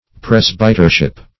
Search Result for " presbytership" : The Collaborative International Dictionary of English v.0.48: Presbytership \Pres"by*ter*ship\, n. The office or station of a presbyter; presbyterate.